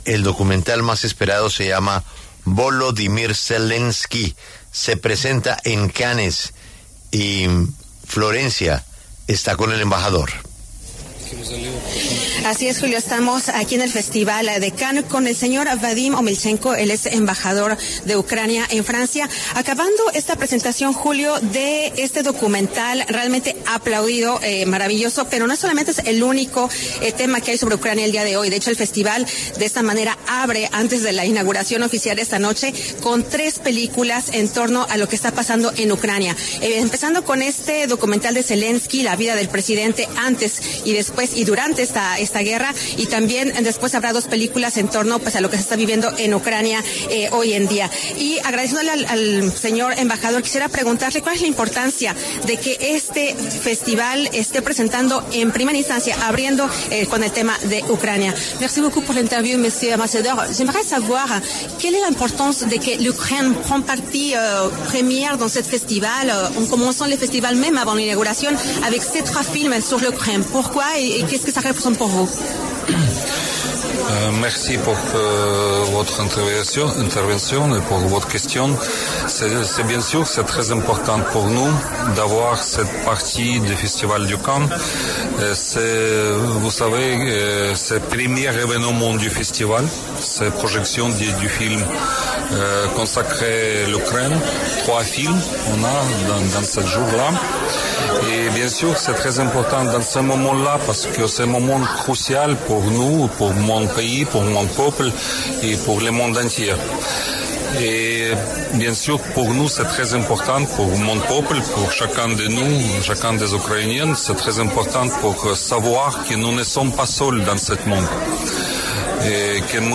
Vadym Omelchenko, embajador ucraniano en Francia, conversó con La W a propósito del documental ‘Zelensky’ que será presentado en el inicio del Festival de Cannes 2025.